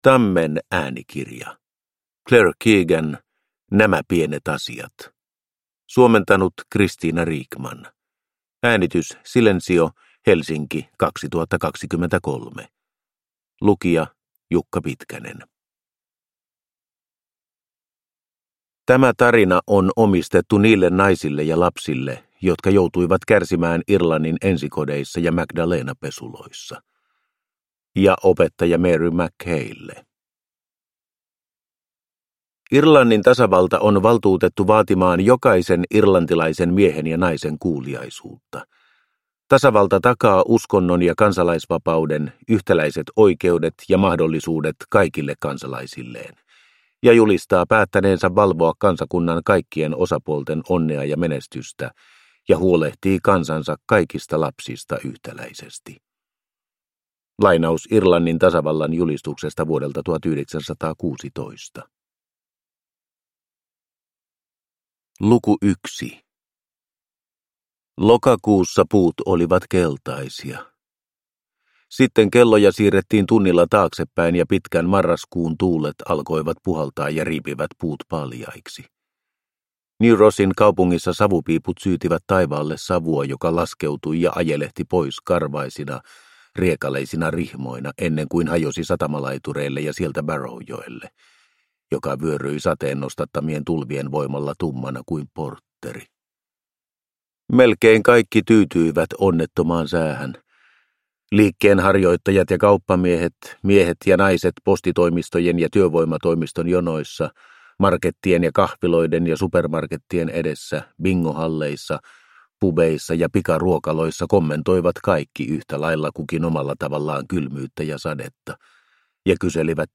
Nämä pienet asiat – Ljudbok – Laddas ner